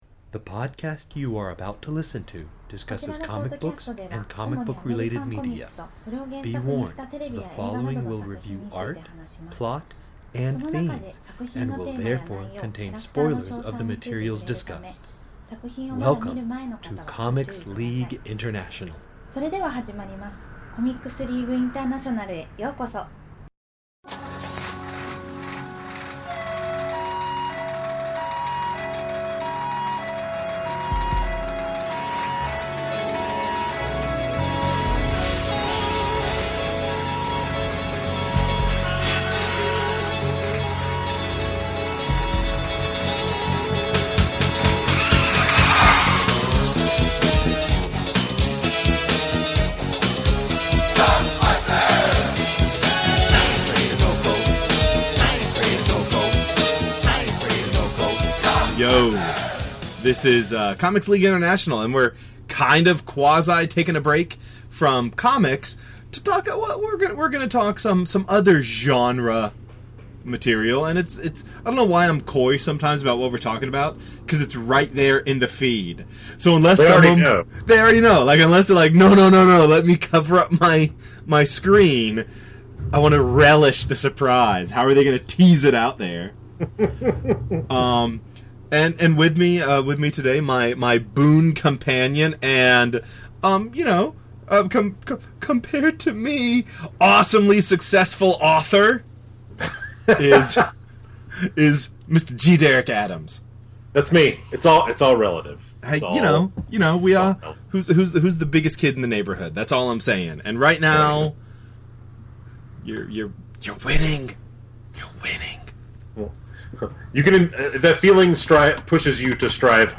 CLI 37: Ghostbusters II commentary (Part 1)